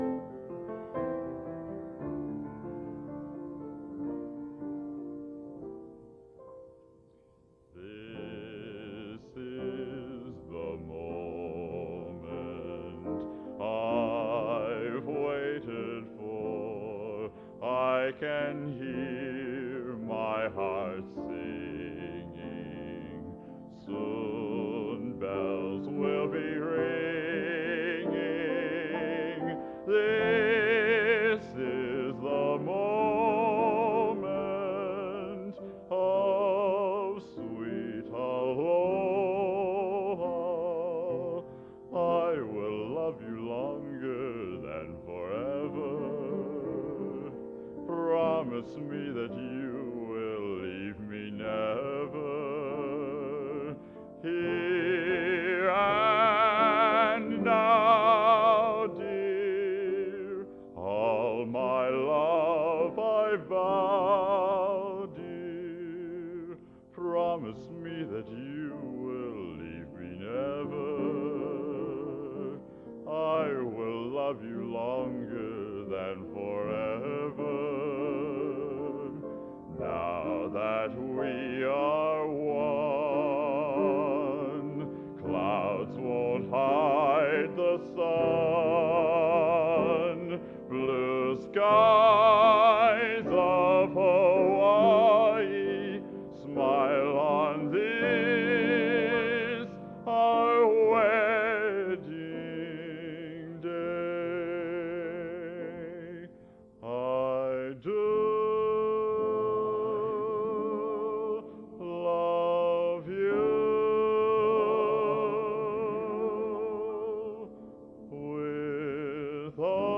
Genre: | Type: End of Season |Solo